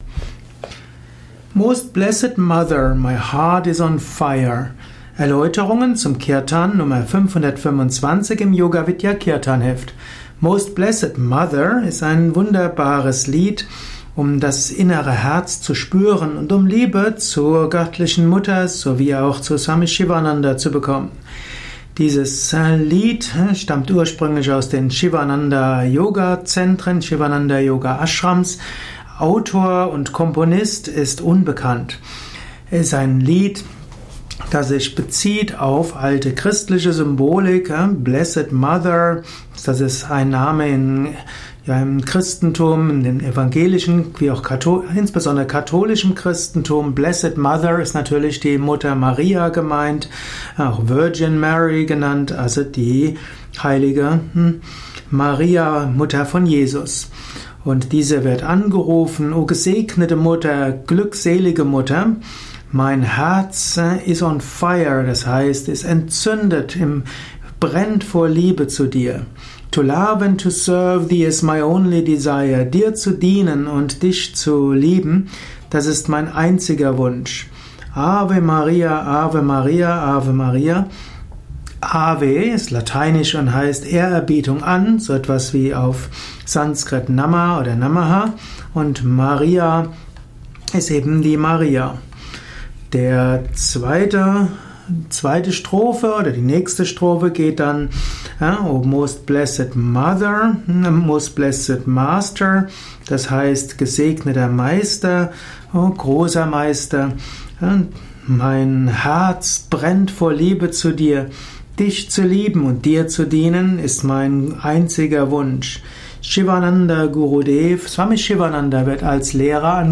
Ein mp3 Audio Kurzvortrag zum Most Blessed Mother Lied , Nummer 525
im Yoga Vidya Kirtanheft , Tonspur eines Kirtan Lehrvideos.